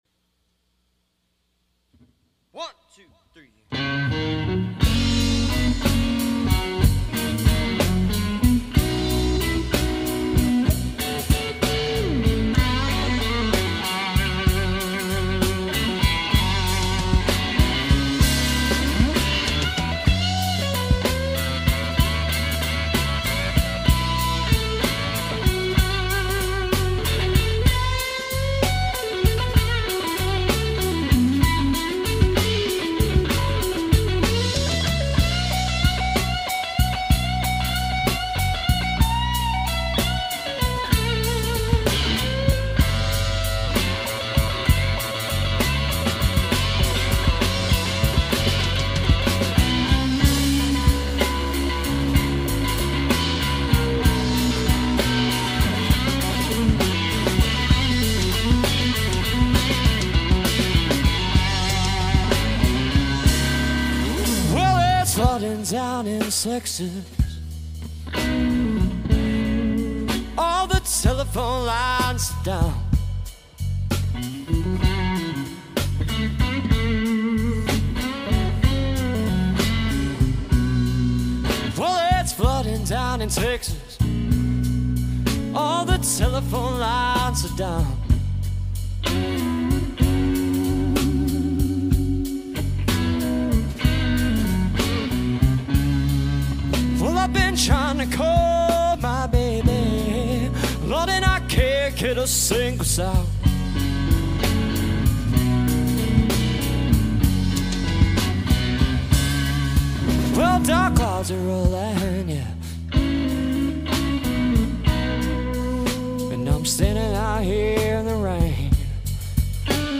tribute livestream
variety rock and blues band
Guitar and Vocals
Bass
drums
bluesy, funky, & rocking feel